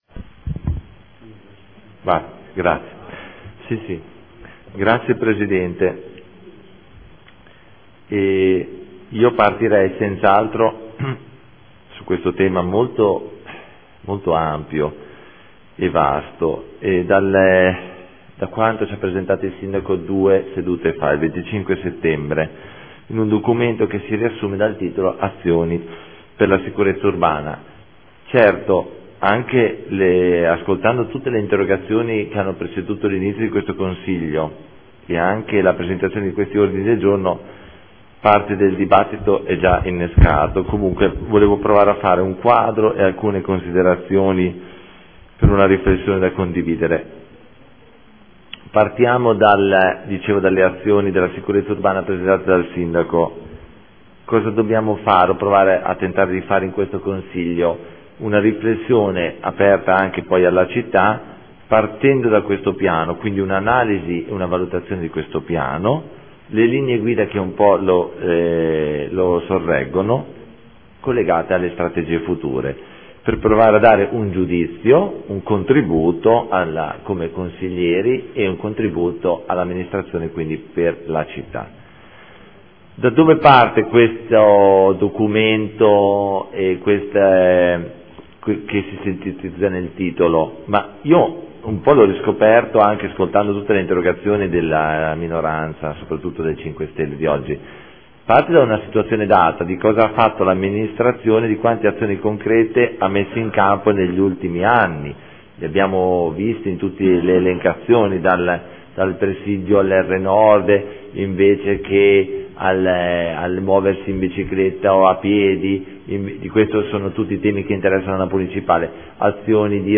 Antonio Carpentieri — Sito Audio Consiglio Comunale
Seduta del 9/10/2014 Dibattito Sicurezza